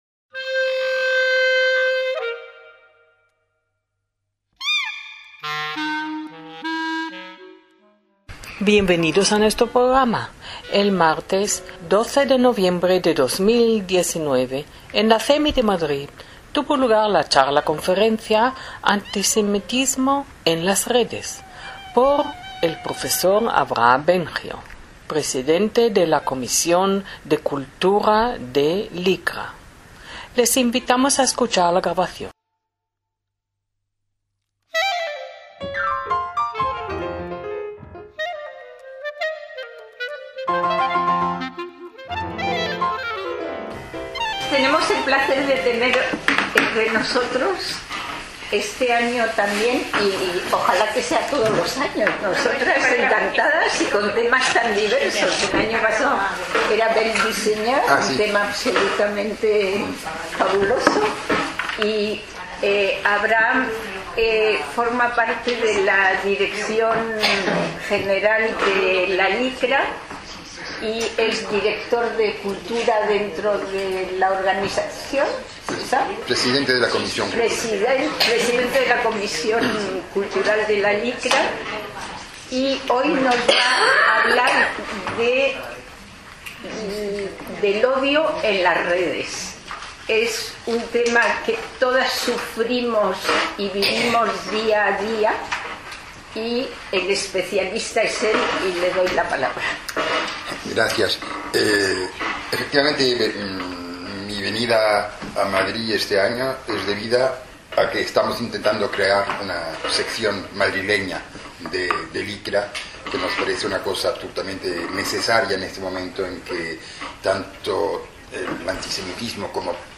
ACTOS EN DIRECTO